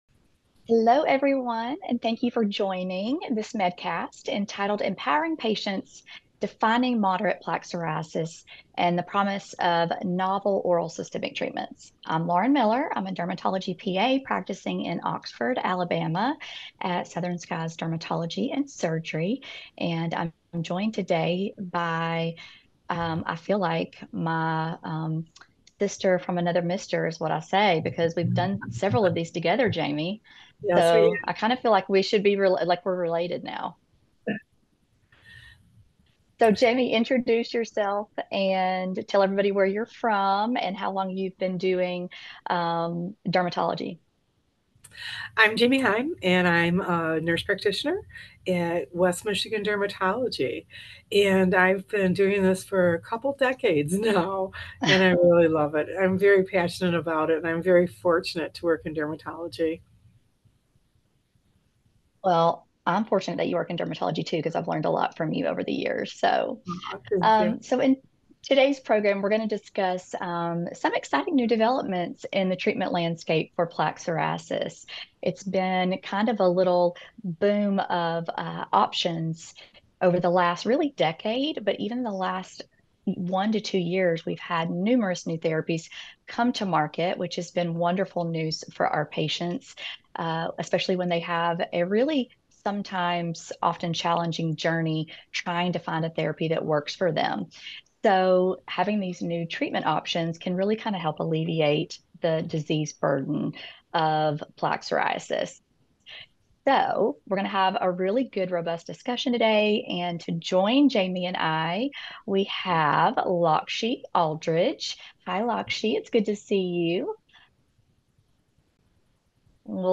Plaque psoriasis experts explore recent advancements in treatment options, patient experiences, and the potential of novel oral systemic therapies to reduce the burden of this prevalent chronic condition.